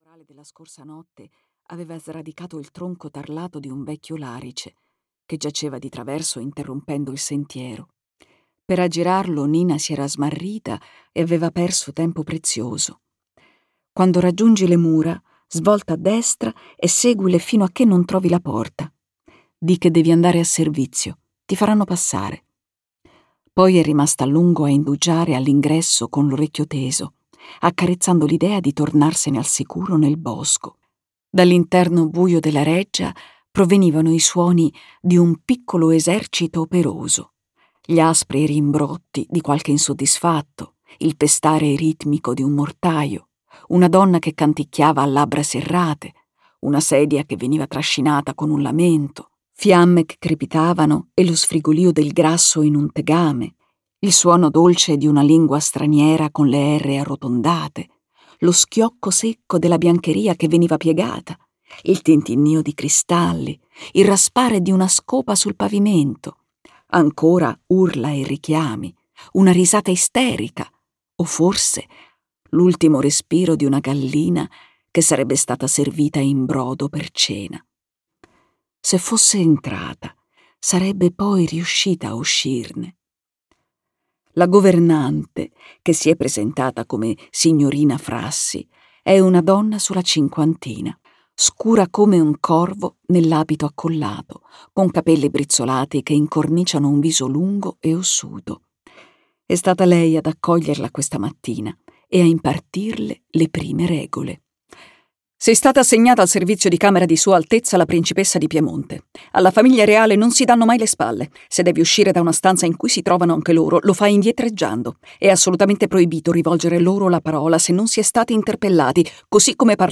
"La prima regina" di Alessandra Selmi - Audiolibro digitale - AUDIOLIBRI LIQUIDI - Il Libraio